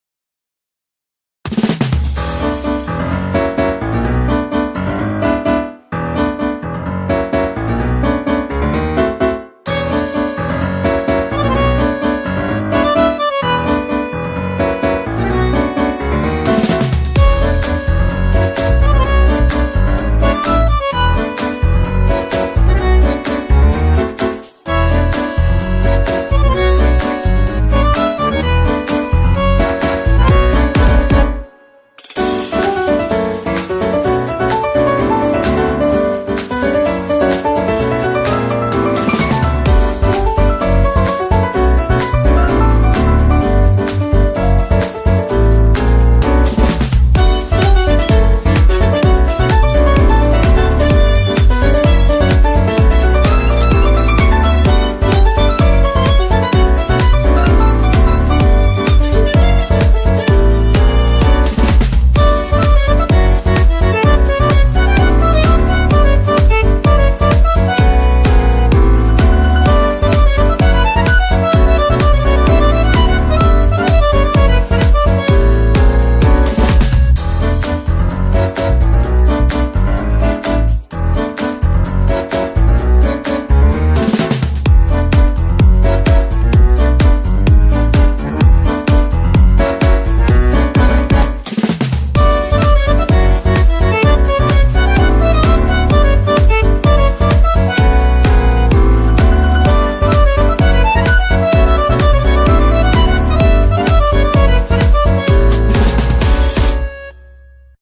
BPM128
Audio QualityMusic Cut
Gypsy-like, I guess.